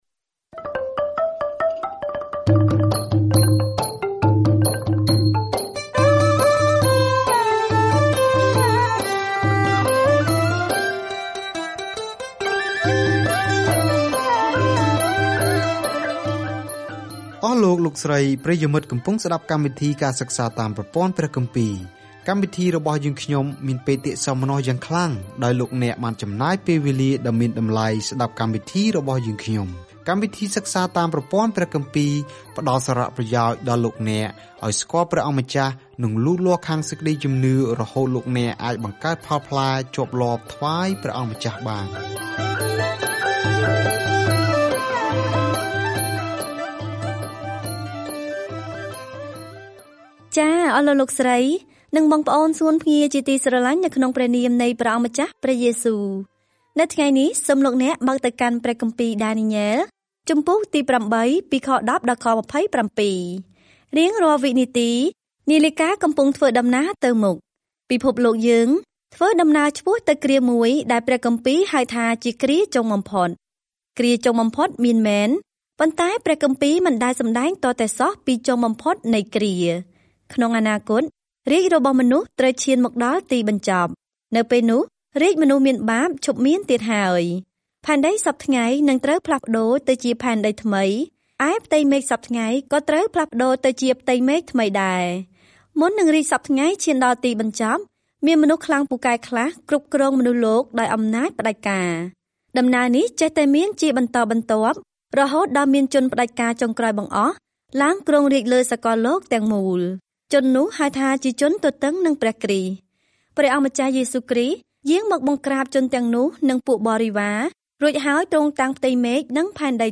សៀវភៅដានីយ៉ែលគឺជាសៀវភៅជីវប្រវត្តិរបស់បុរសម្នាក់ដែលបានជឿព្រះ និងជាទស្សនវិស័យព្យាករណ៍អំពីអ្នកដែលនឹងគ្រប់គ្រងពិភពលោកនៅទីបំផុត។ ការធ្វើដំណើរជារៀងរាល់ថ្ងៃតាមរយៈដានីយ៉ែល នៅពេលអ្នកស្តាប់ការសិក្សាជាសំឡេង ហើយអានខគម្ពីរដែលជ្រើសរើសពីព្រះបន្ទូលរបស់ព្រះ។